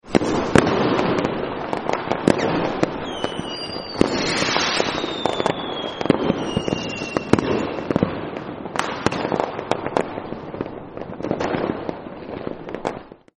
fireworks-briefl.mp3